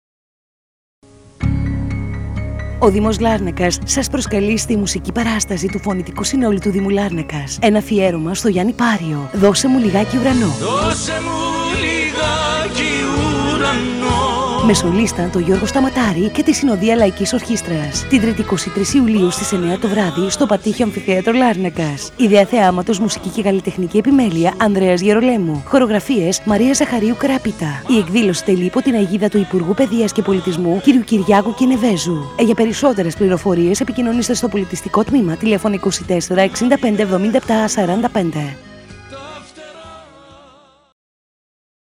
Απο την συναυλία αφιέρωμα
με το φωνητικό σύνολο του Δήμου Λάρνακας